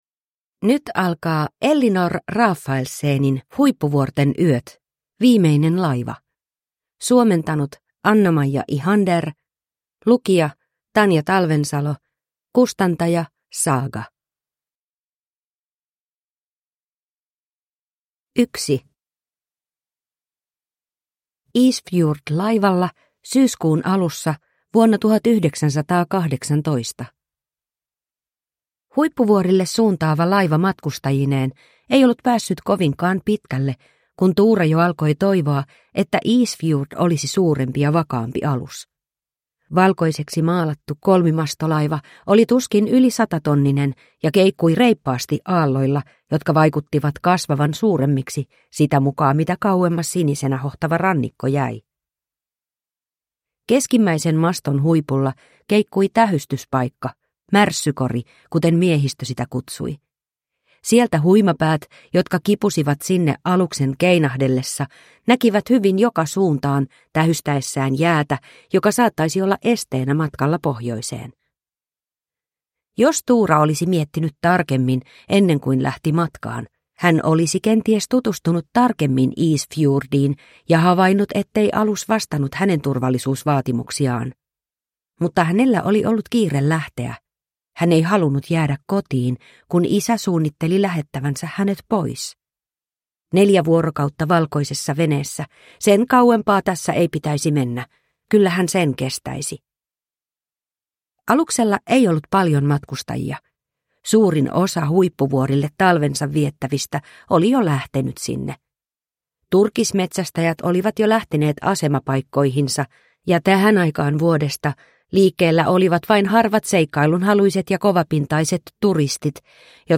Viimeinen laiva –  Huippuvuorten yöt 2 – Ljudbok